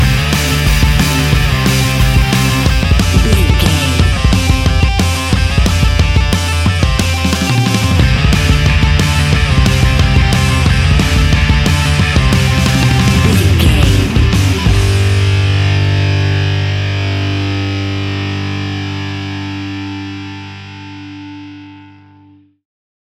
Fast paced
Ionian/Major
distortion
punk metal
Rock Bass
Rock Drums
heavy drums
distorted guitars
hammond organ